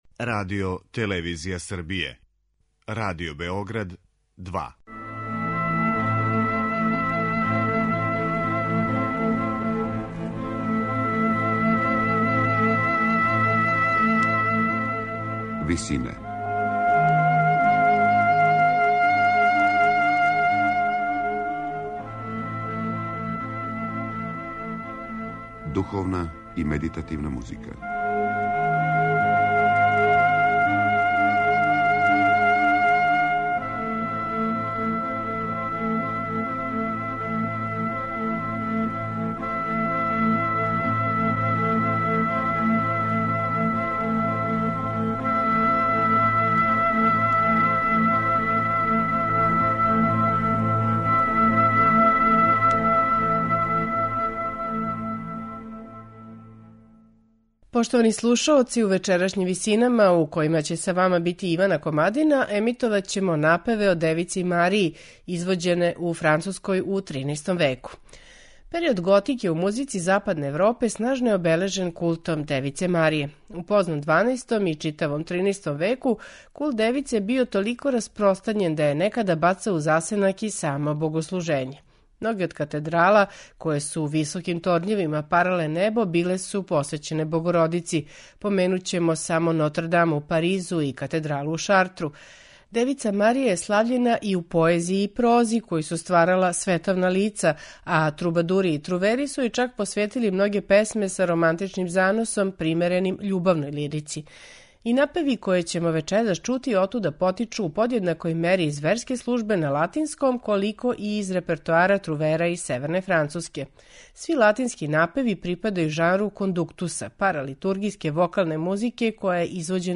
Емитоваћемо напеве о Девици Марији извођене у Француској у 13. веку
Слушаћете их у интерпретацији ансамбла Anonimous 4.